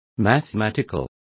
Complete with pronunciation of the translation of mathematical.